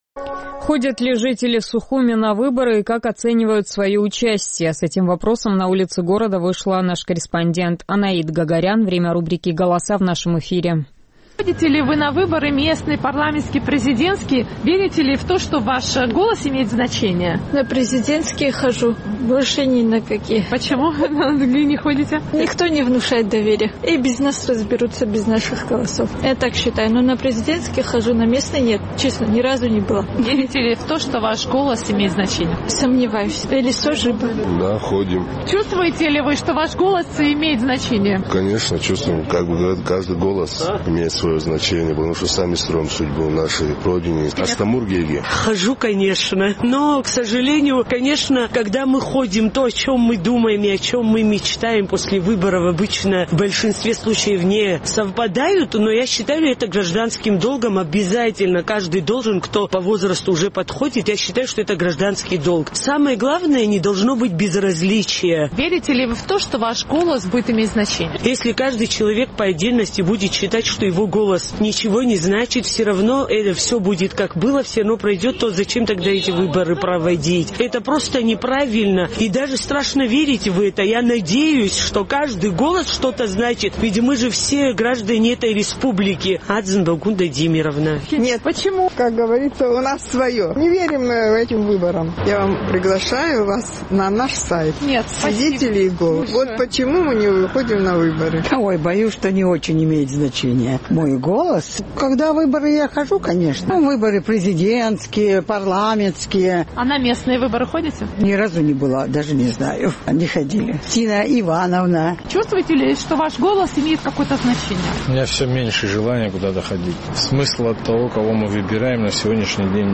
Наш сухумский корреспондент поинтересовалась у местных жителей, ходят ли они на выборы и верят ли в то, что их голос имеет значение.